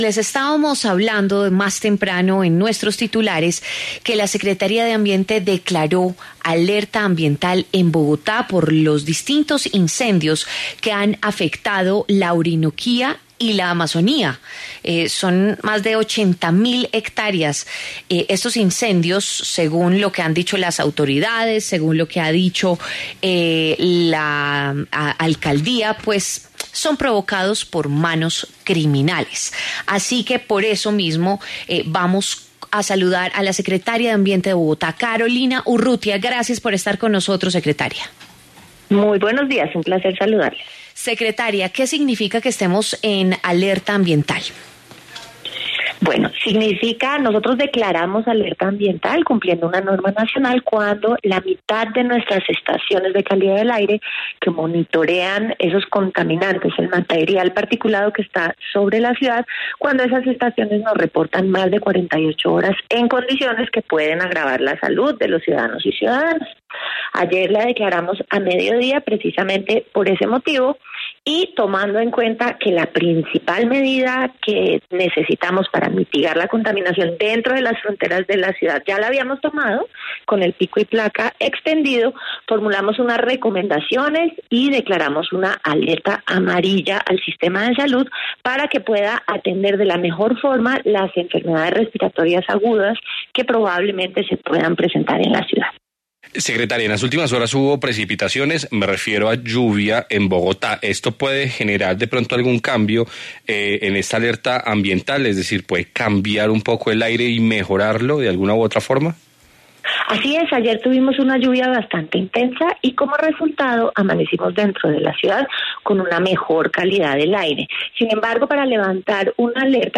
Carolina Urrutia, secretaria de Ambiente de Bogotá, habló en W Fin de Semana sobre los motivos que llevaron a tomar esa decisión en la capital del país.